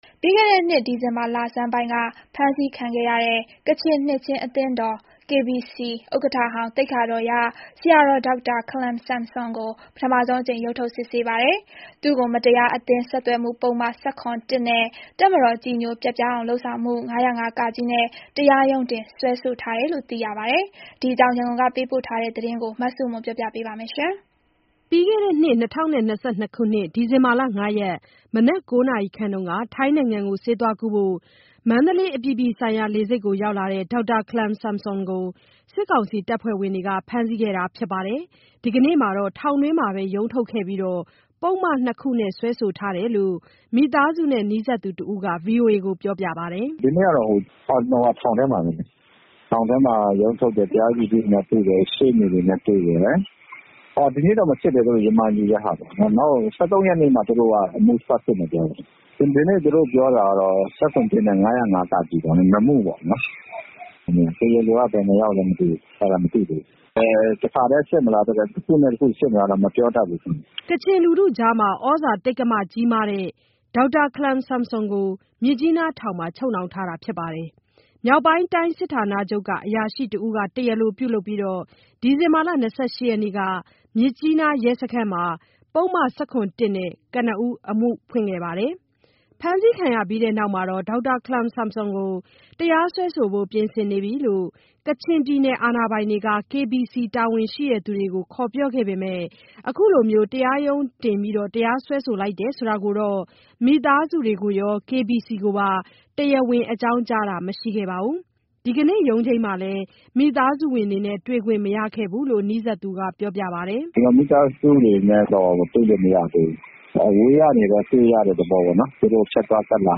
ဒီအကွောငျး ရနျကုနျကပေးပို့တဲ့သတငျး